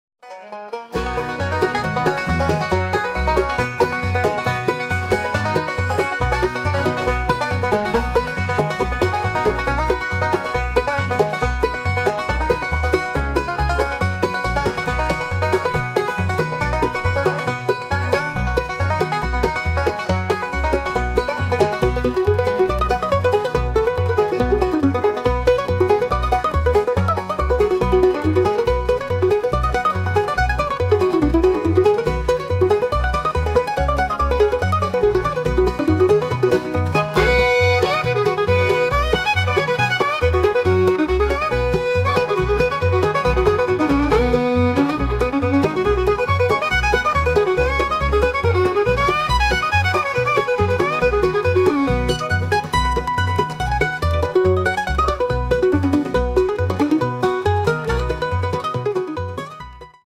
Musik
Schneller instrumental Sound mit vorwiegend Banjoklang.